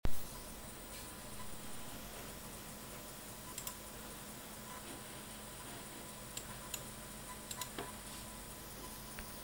unregelmäßiges fiepsen auf Monitorboxen (diagnosehilfe)